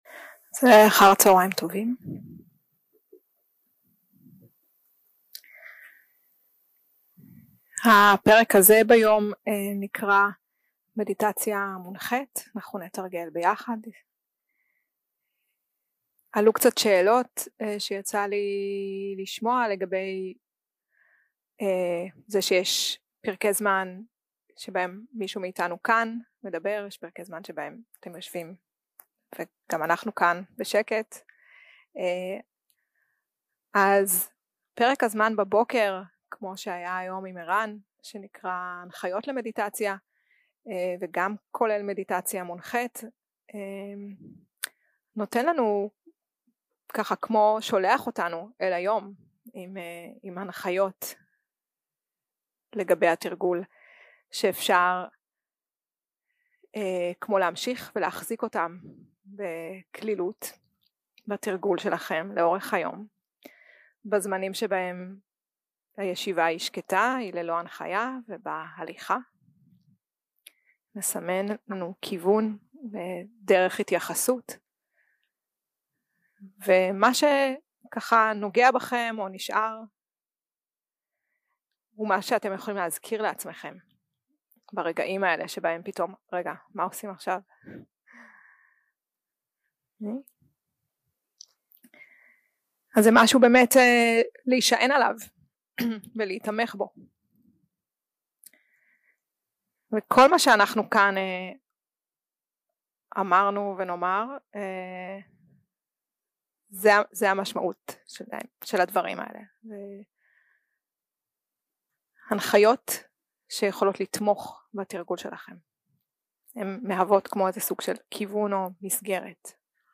יום 2 – הקלטה 3 – צהריים – מדיטציה מונחית | Tovana
Dharma type: Guided meditation